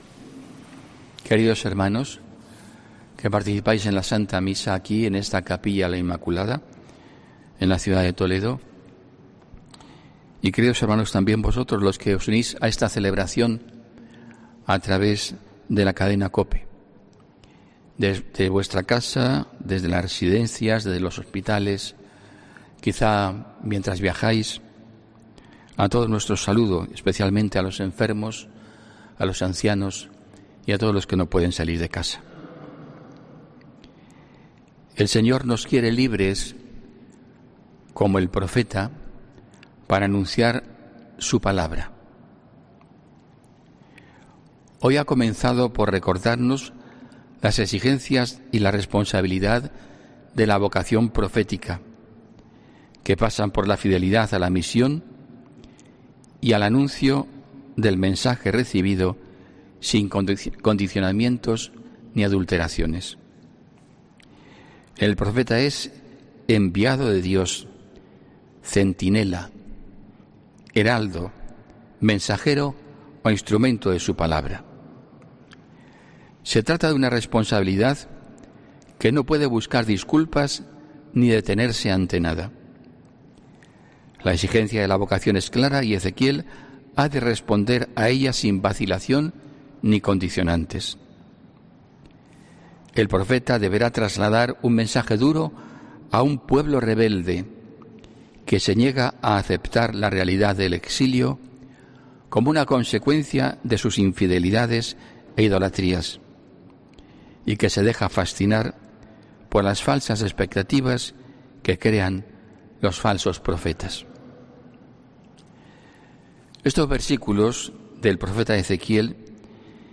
HOMILÍA 6 DE SEPTIEMBRE DE 2020